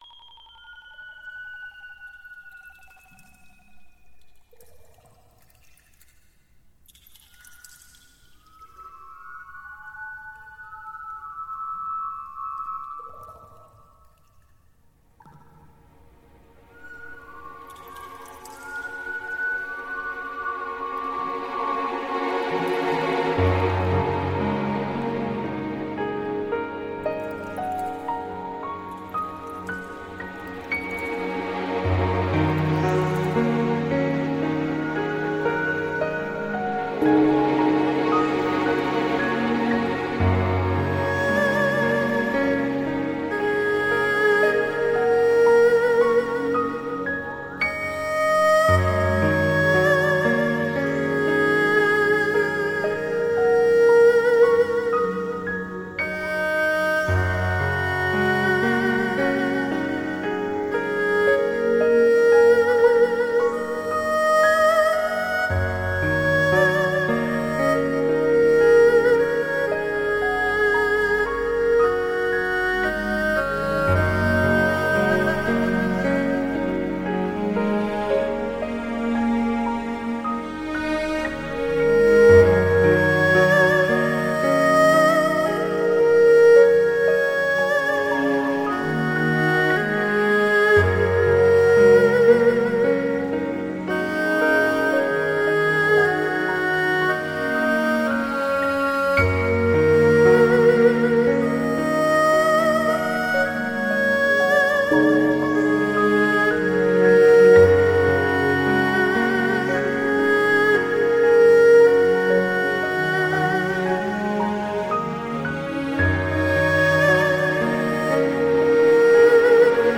独特的那种电子味道是他的标志
那是各种的钟声，那种古刹寺庙独有的钟声——悠远的，深沉的撞击着我的内心